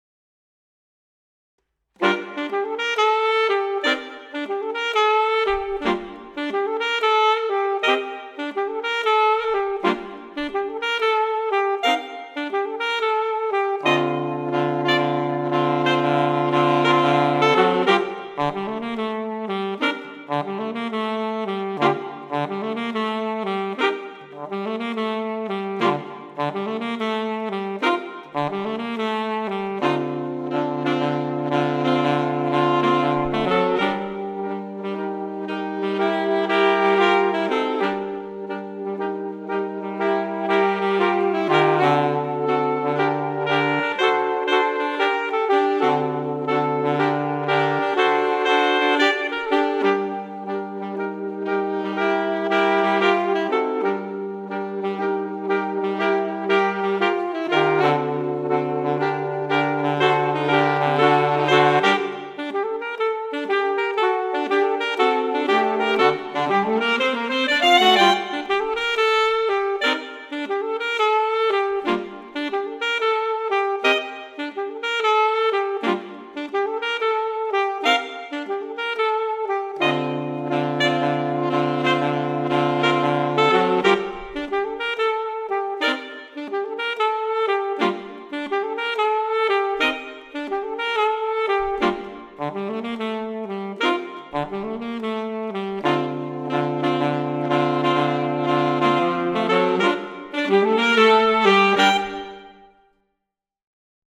Eight pieces for four altos or three altos plus tenor